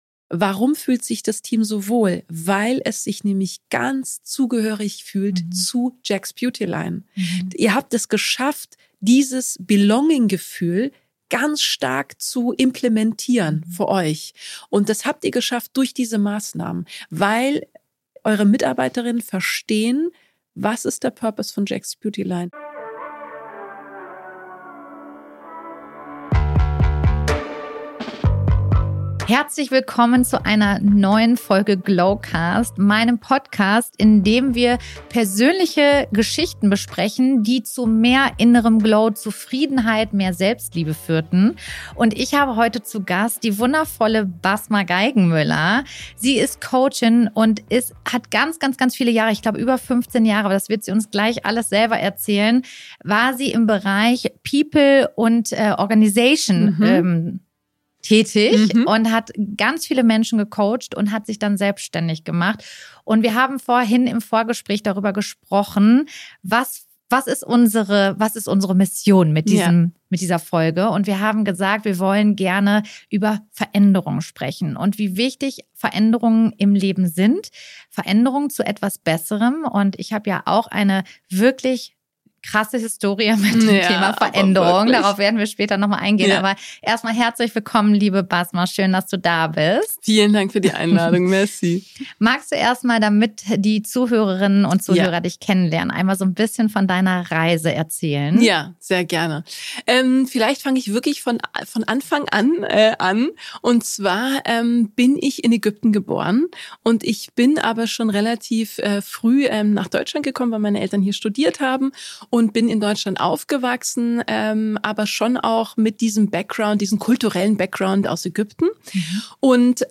In diesem inspirierenden Gespräch geht es um die Bedeutung von persönlicher und beruflicher Veränderung, die in uns selbst beginnt.